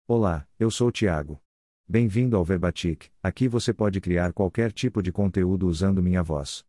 ThiagoMale Brazilian Portuguese AI voice
Thiago is a male AI voice for Brazilian Portuguese.
Voice sample
Male
Thiago delivers clear pronunciation with authentic Brazilian Portuguese intonation, making your content sound professionally produced.